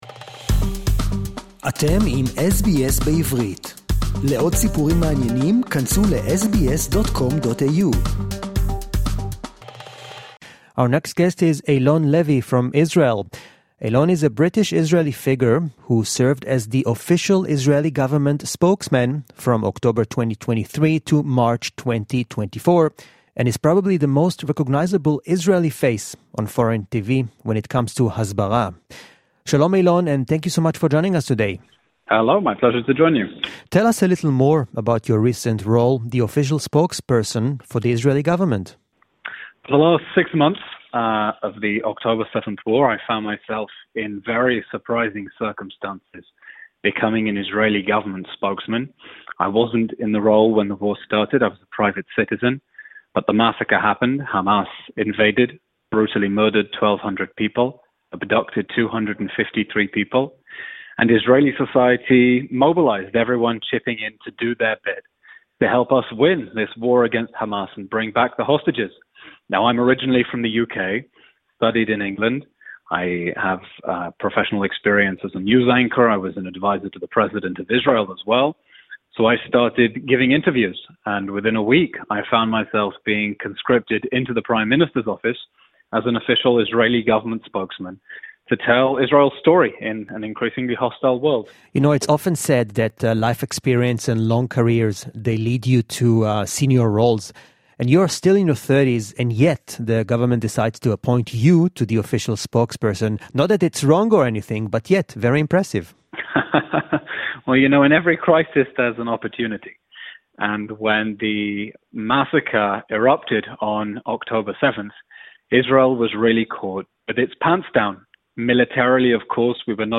Eylon Levy served as the official Israeli government spokesperson until March 2024. (English Interview)